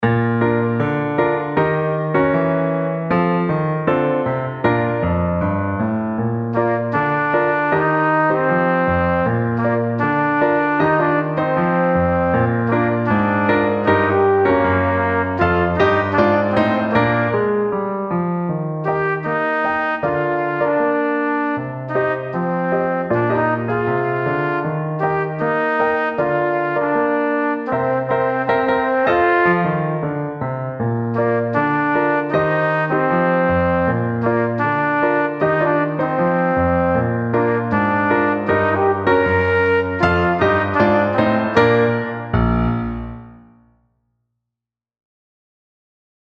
Performance